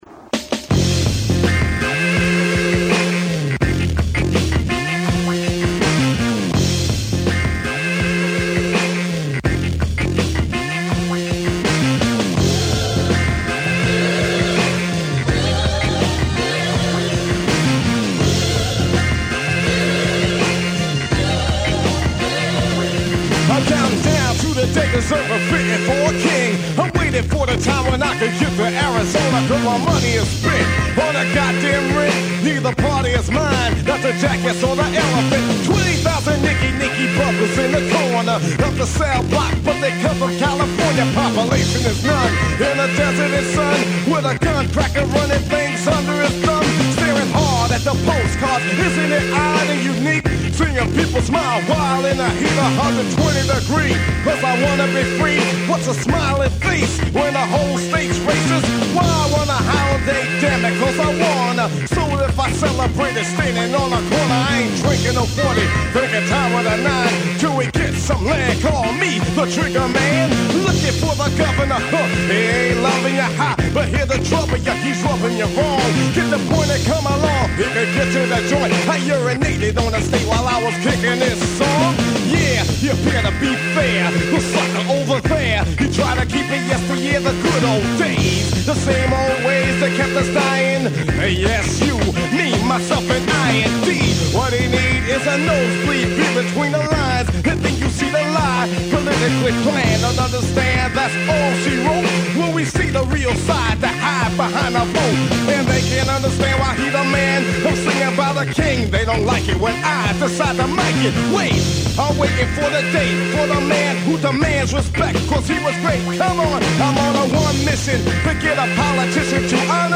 The conversations have given a great deal of context to the debate about Immigration reform and border policies. Teachers 4 Class War airs every Monday at 6 PM on Free Radio Santa Cruz 101.1 FM.